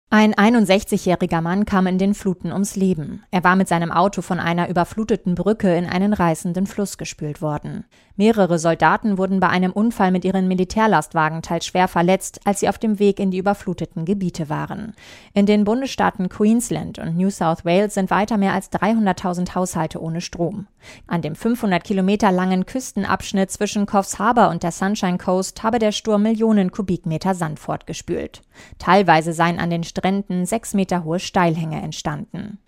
Nachrichten „Alfred“ hat Millionen Kubikmeter Sand weggespült